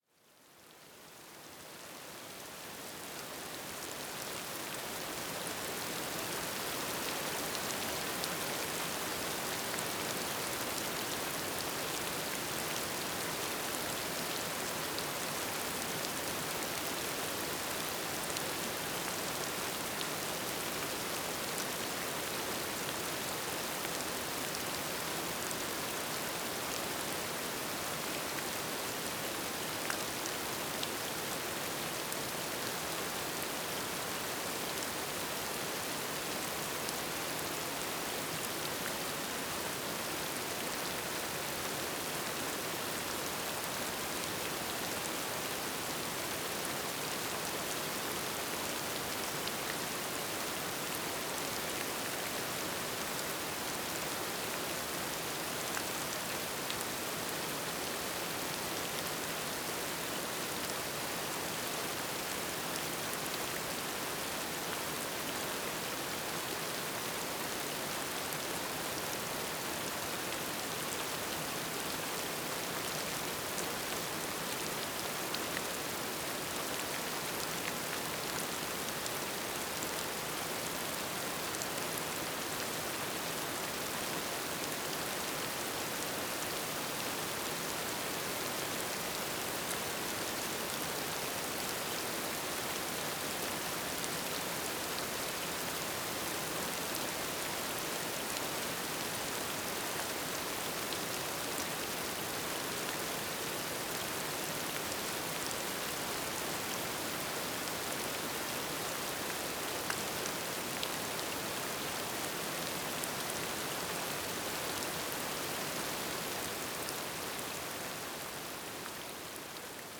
rain.ogg